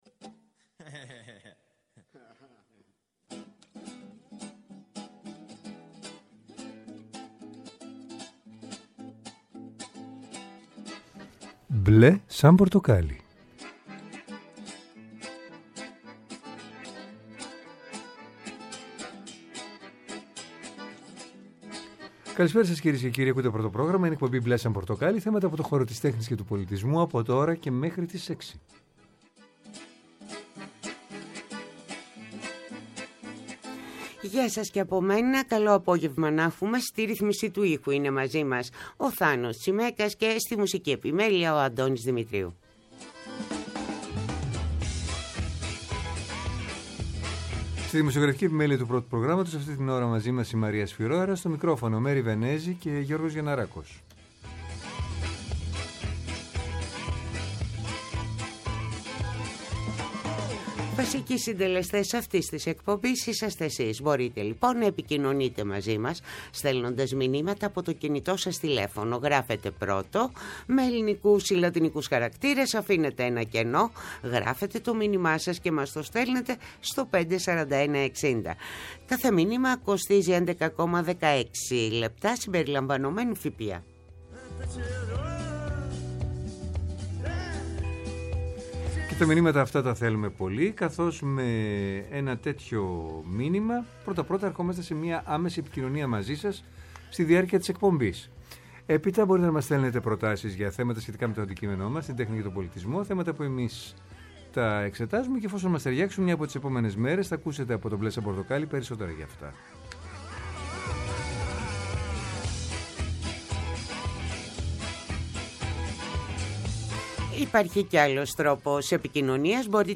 Θέατρο, κινηματογράφος, μουσική, χορός, εικαστικά, βιβλίο, κόμικς, αρχαιολογία, φιλοσοφία, αισθητική και ό,τι άλλο μπορεί να είναι τέχνη και πολιτισμός, καθημερινά από Δευτέρα έως Πέμπτη 5-6 το απόγευμα από το Πρώτο Πρόγραμμα. Μια εκπομπή με εκλεκτούς καλεσμένους, άποψη και επαφή με την επικαιρότητα.